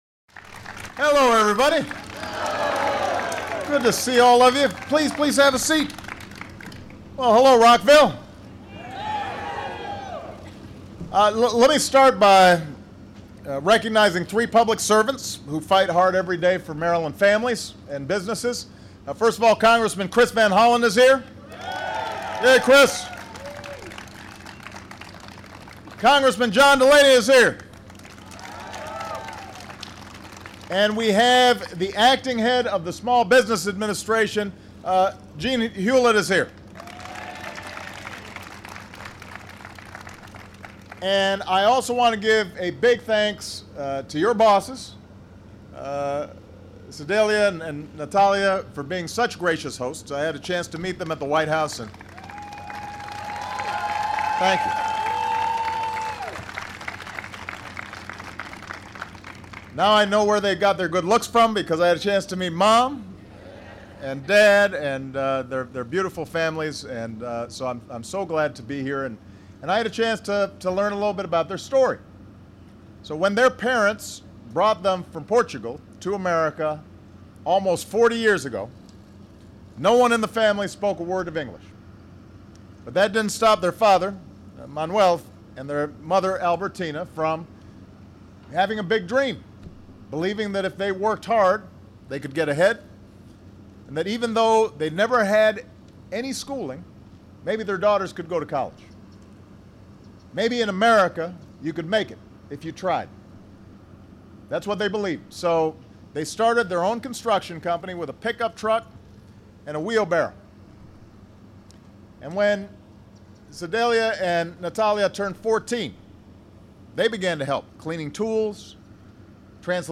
U.S. President Barack Obama speaks on the economic impact of the government shutdown